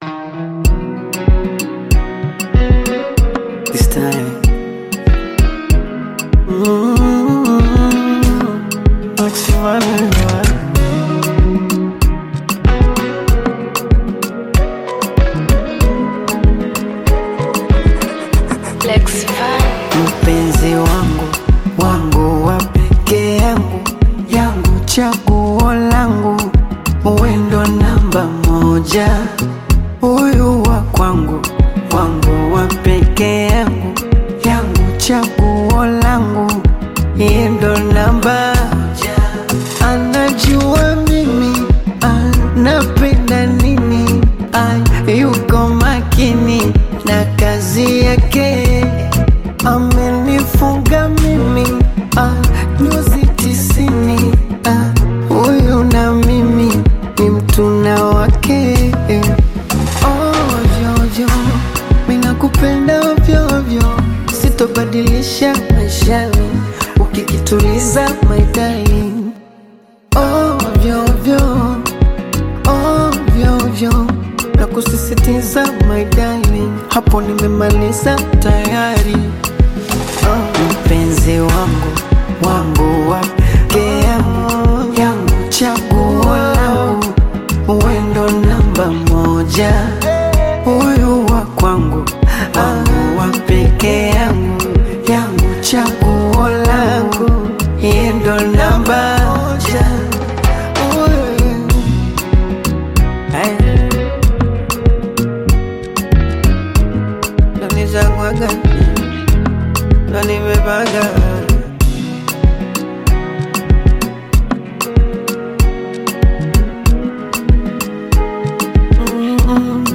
Bongo Flava song
This catchy new song